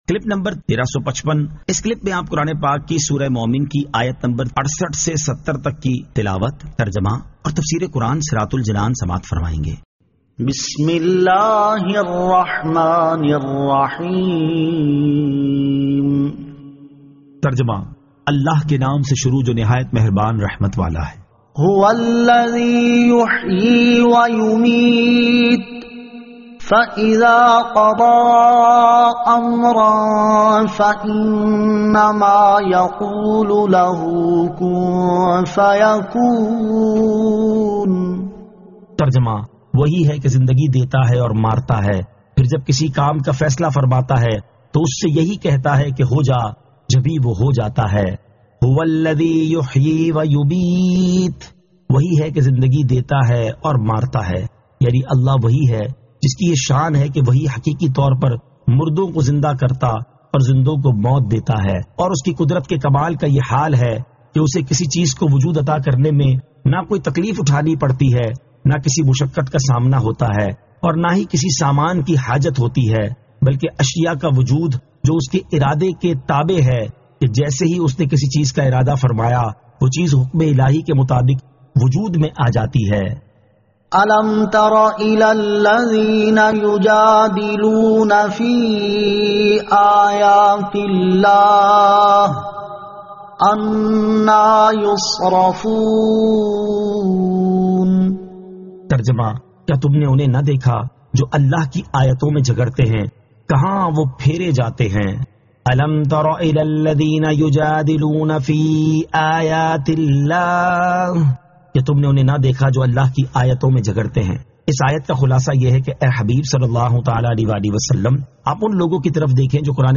Surah Al-Mu'min 68 To 70 Tilawat , Tarjama , Tafseer
2023 MP3 MP4 MP4 Share سُوَّرۃُ الْمُؤمِنِ آیت 68 تا 70 تلاوت ، ترجمہ ، تفسیر ۔